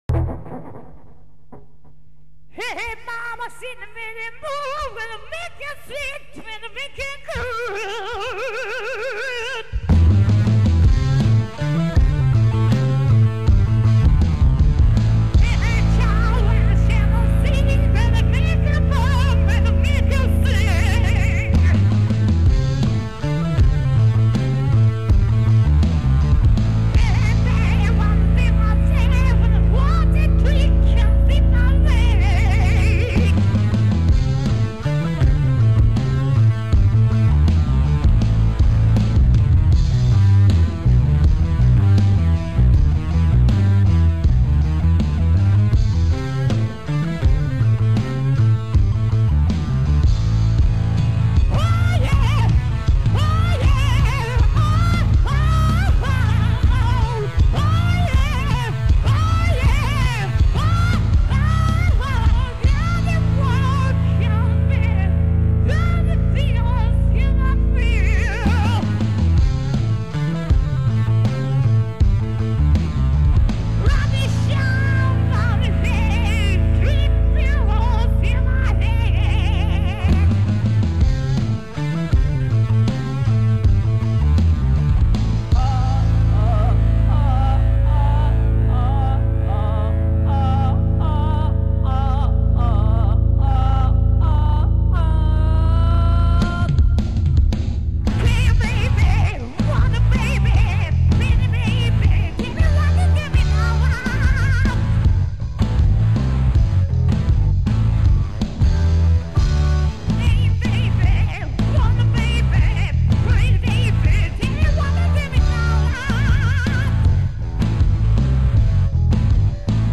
で、猫背、歌詞ウロ覚えなんで、毎度の言い訳、声出てないですが、
ので、１テイク、２コーラスのみ、やり直しはナシの２分位です。
ギターも弾きたいと思いましたが、容量の余裕が有りませんでした。